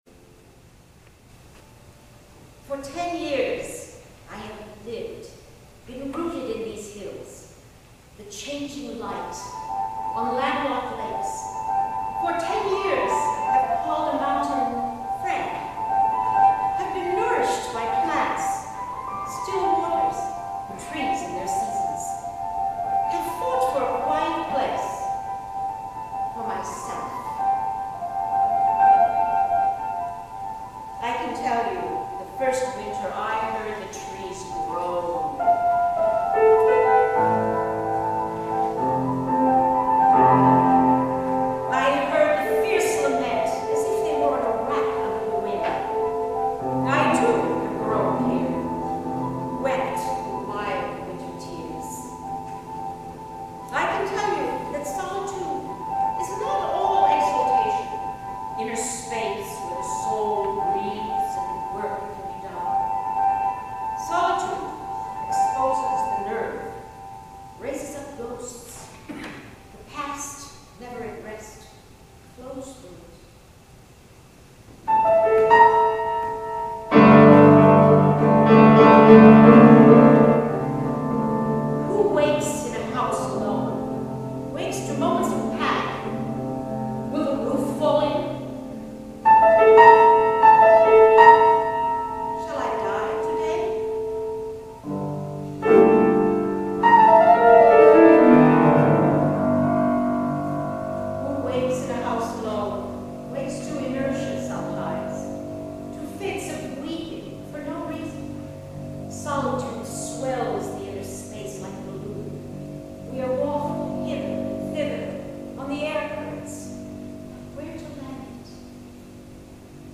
for Narrator and Piano (2007)
piano.